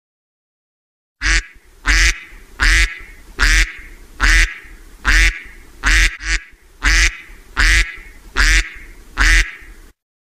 Ducks sound || all about sound effects free download